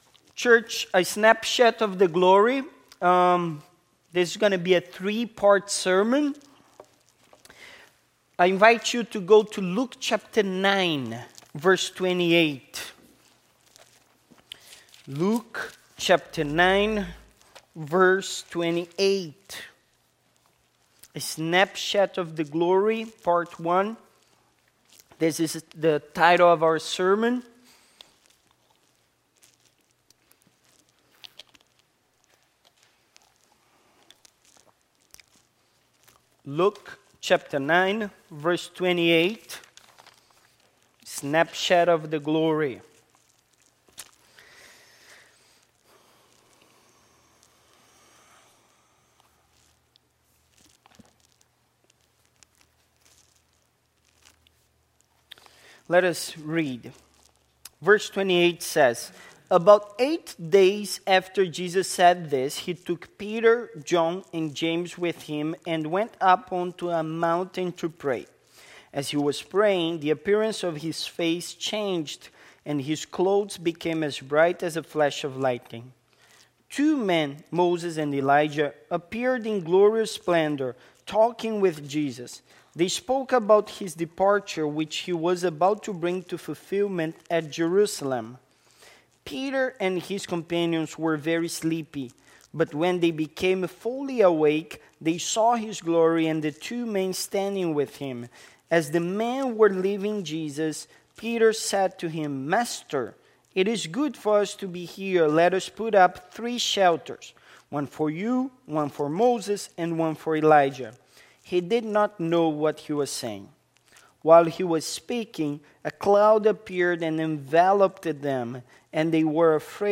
Passage: Luke 9:28–36 Service Type: Sunday Morning